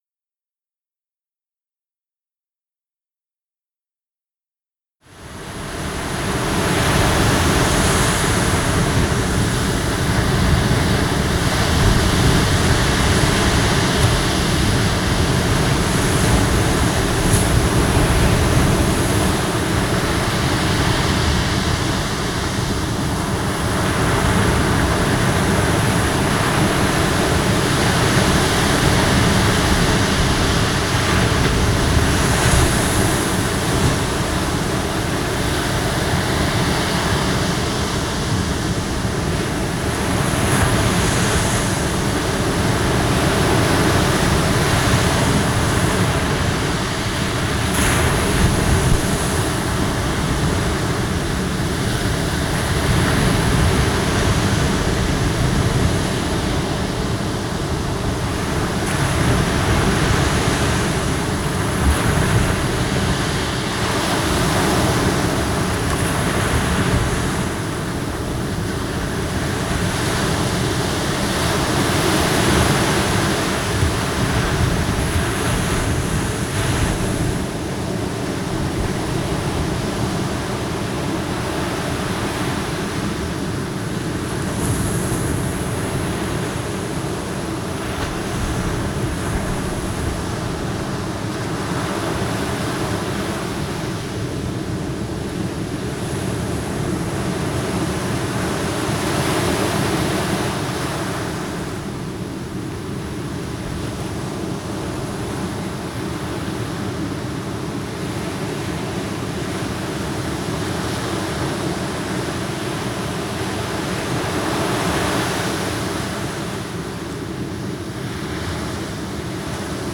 Receding Ocean Nature Sound
Receding-Nature-Ocean-Dolby-Atmos-HP-15-mins.mp3